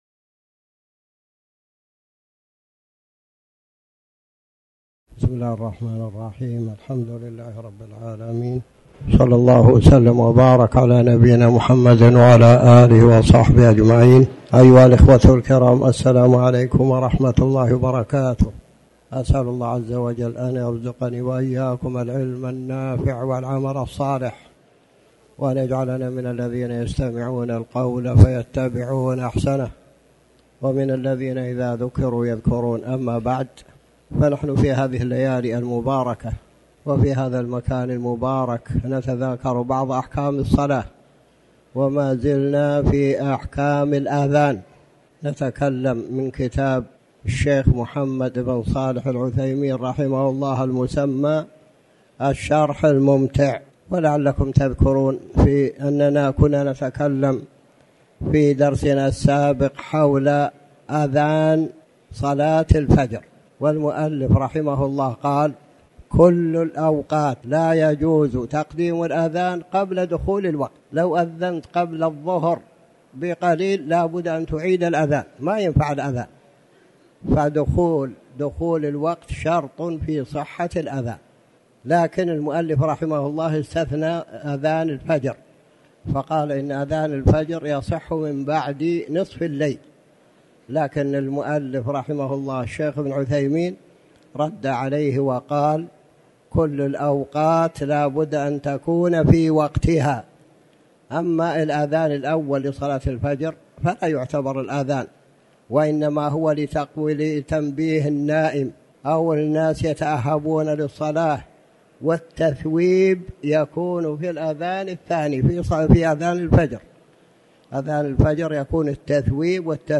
تاريخ النشر ١٨ صفر ١٤٤٠ هـ المكان: المسجد الحرام الشيخ